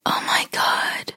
На этой странице собраны звуки с фразой «Oh my god» в разных эмоциональных оттенках: удивление, восторг, шок.
Шепотом в тишине